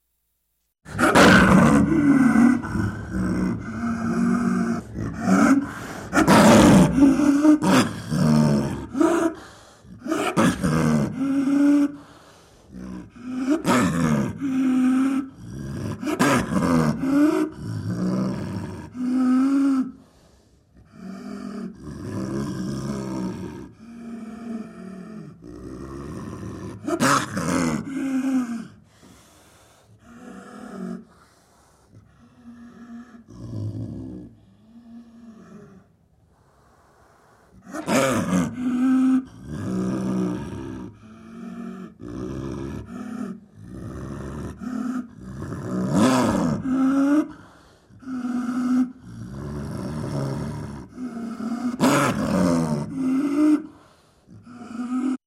Звуки снежного барса
Глухой рев древнего снежного барса